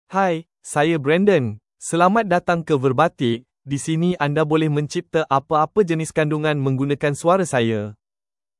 BrandonMale Malay AI voice
Brandon is a male AI voice for Malay (Malaysia).
Voice sample
Listen to Brandon's male Malay voice.
Male
Brandon delivers clear pronunciation with authentic Malaysia Malay intonation, making your content sound professionally produced.